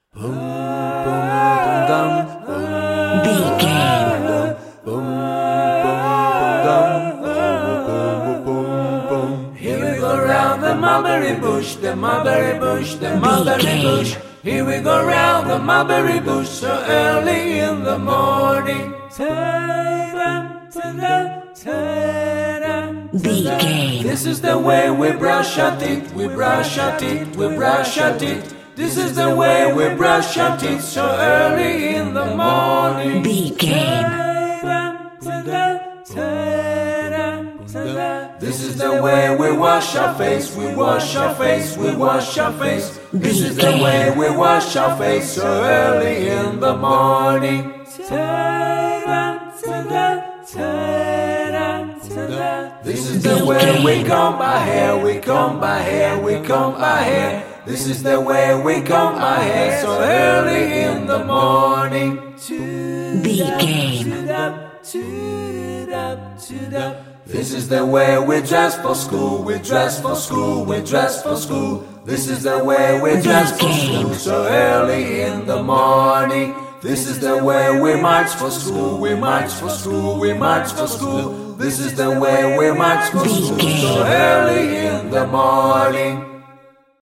Ionian/Major
D♭
nursery rhymes
childlike
cute
happy